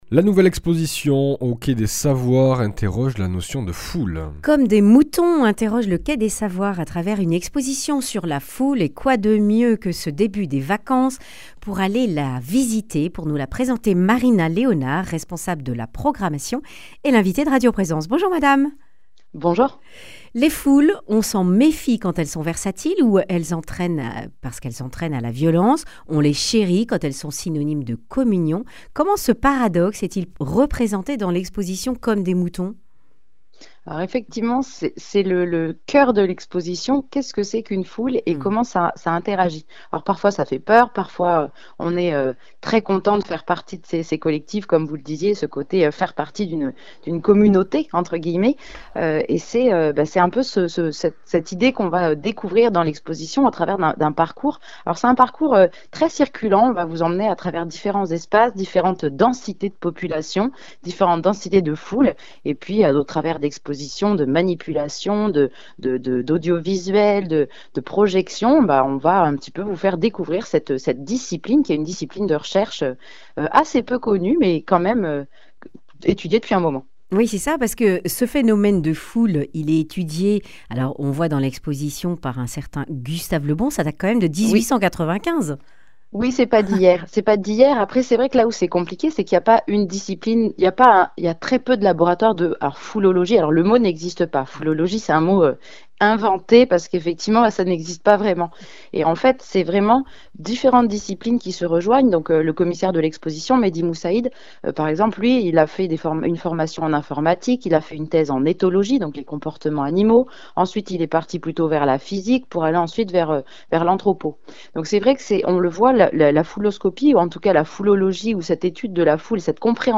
Accueil \ Emissions \ Information \ Régionale \ Le grand entretien \ Comme des moutons ?